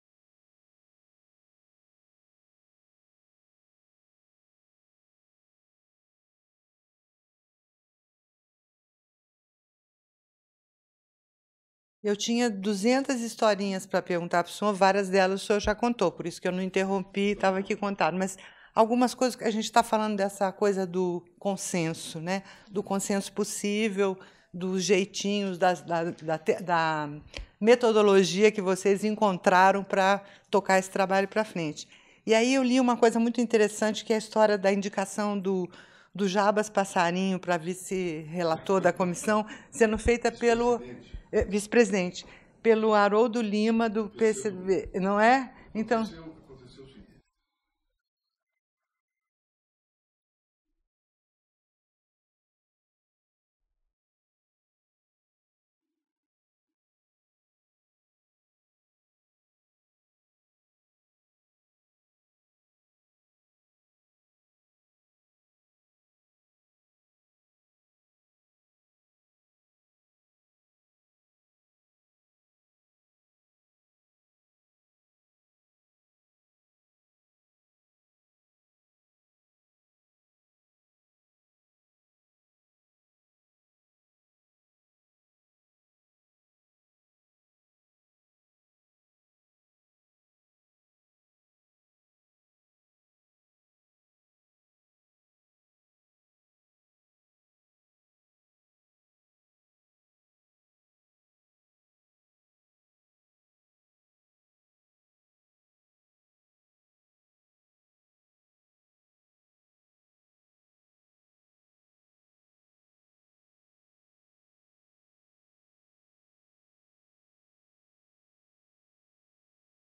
entrevista-ex-senador-nelson-jobim-bloco-4.mp3